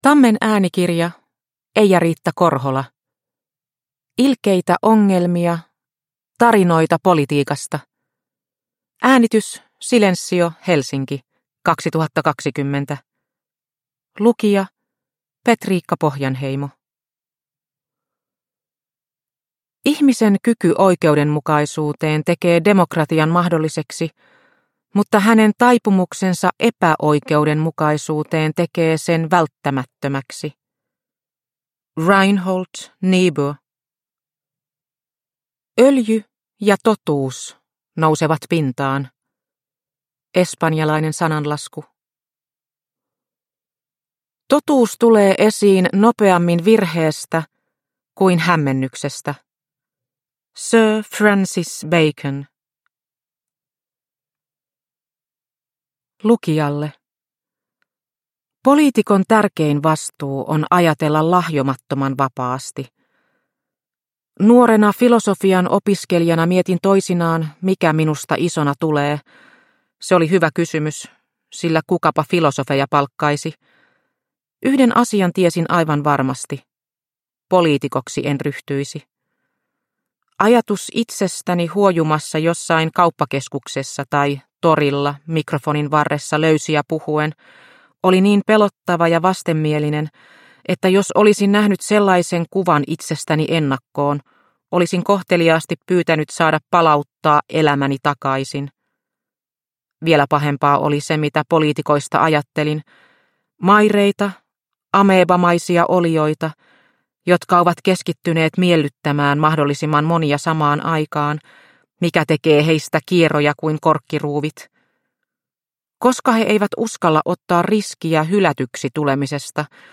Ilkeitä ongelmia - Tarinoita politiikasta – Ljudbok – Laddas ner